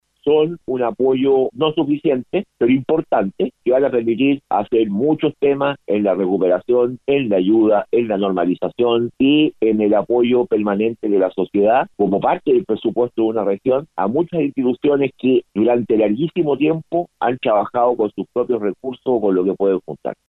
Por su parte, el consejero regional Juan Arriagada, sostuvo que los más de 400 millones de pesos no son suficientes para solucionar la problemática de la rehabilitación e inclusión para personas con discapacidad, pero que ayudarán a mejorar las condiciones en la región.